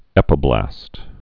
(ĕpə-blăst)